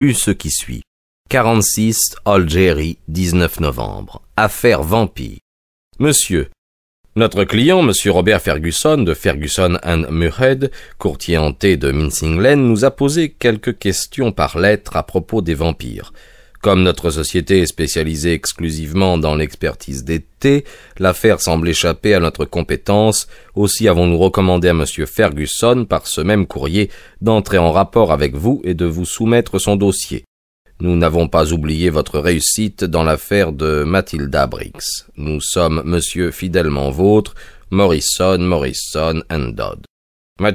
Cette adaptation audio est faite à la manière des grands raconteurs d'histoires, qui font revivre tous les personnages en les interprétant brillamment.